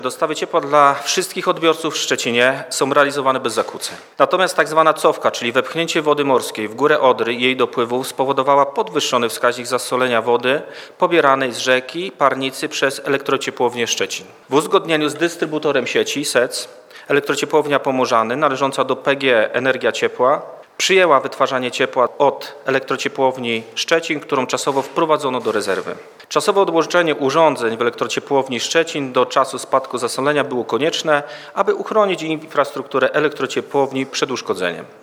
Powodem było zwiększone zasolenie wody niezbędnej w procesie technologicznym. Podczas pilnie zwołanej konferencji prasowej aktualną sytuację przedstawił wojewoda zachodniopomorski Zbigniew Bogucki.